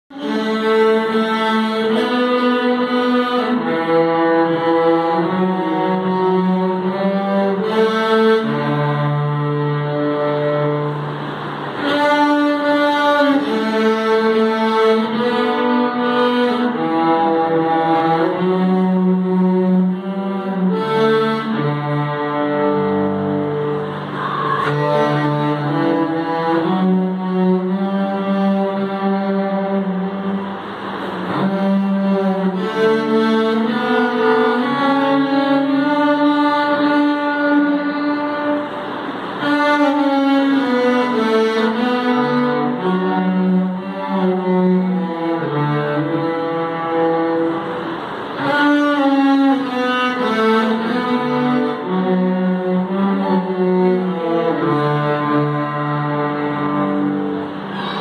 TRIO VIOLONCELO Trio violoncelo
trio_violoncelo.mp3